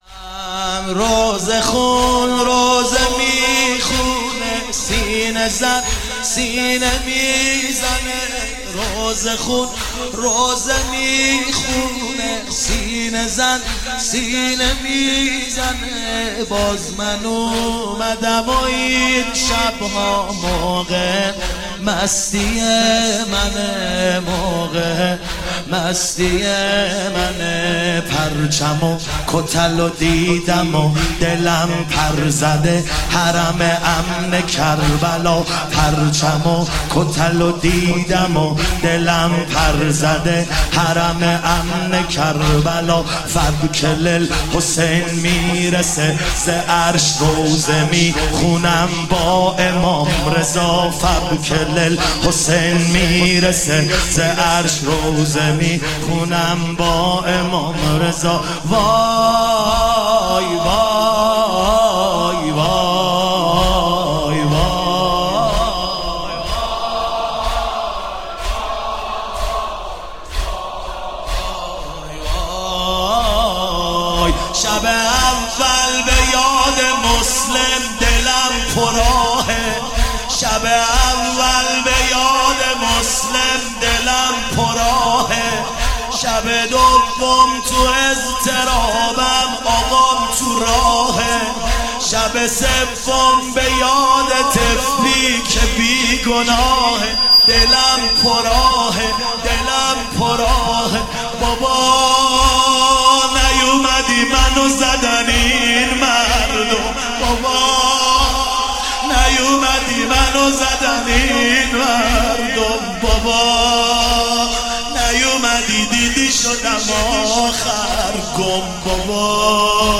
اگر برآید چو مرغی ز پیکر خسته ام پر روضه محمود کریمی